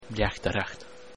If you click on these Irish words, or any of the other words of the day, you can hear how to pronounce them.